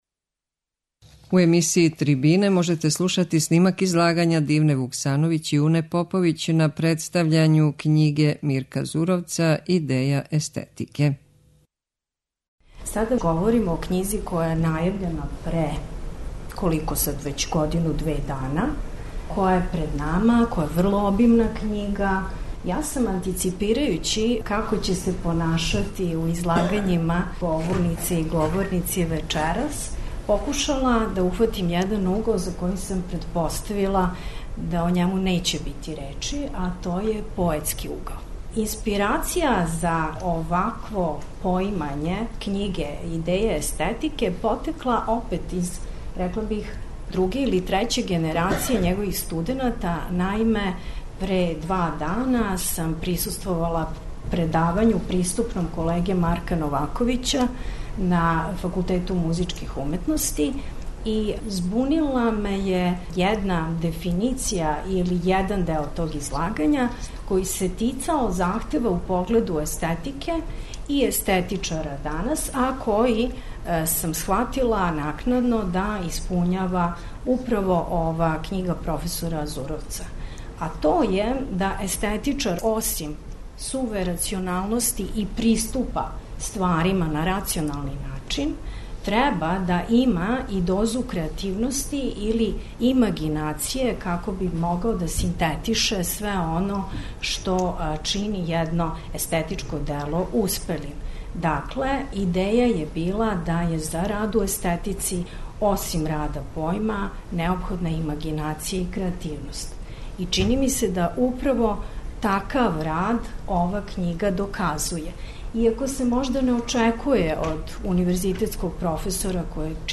Снимак је забележен 21. децембра 2016. у Народној библиотеци Србије у Београду.
Трибине